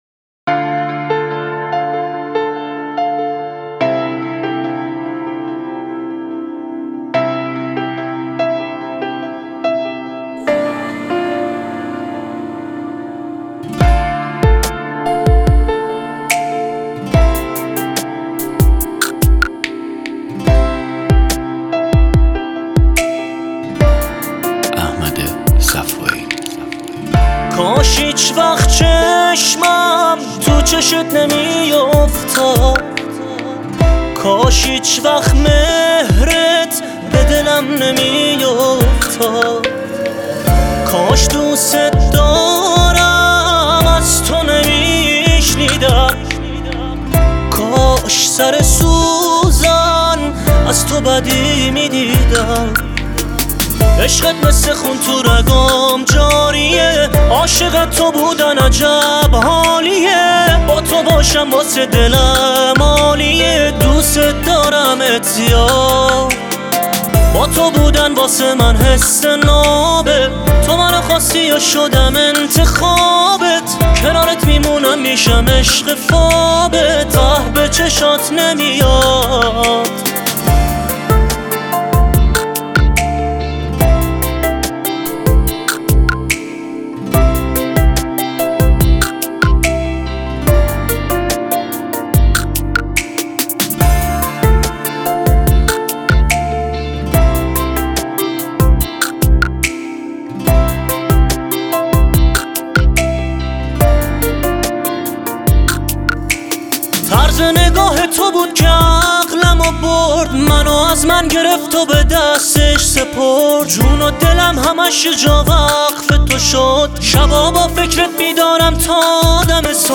ترانه ای عاشقانه و دلنشین با صدای گرم